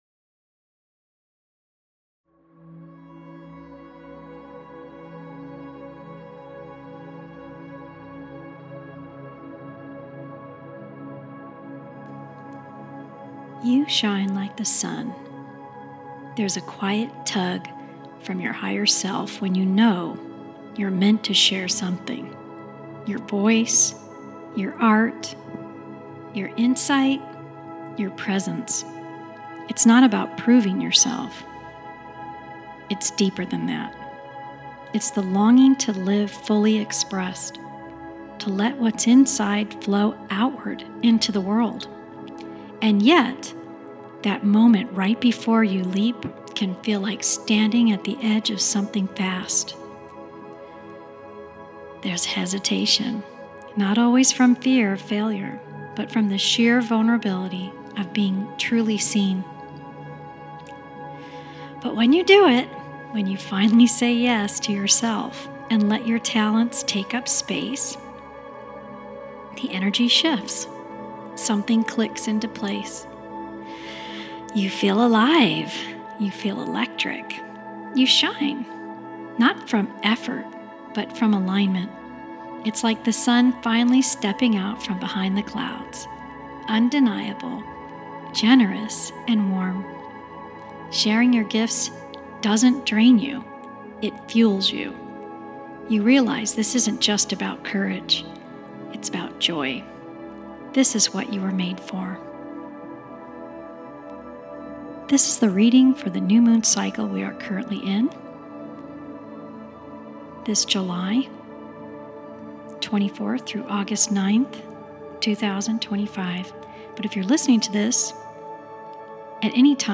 Music by NaturesEye – Energy Uplift Meditation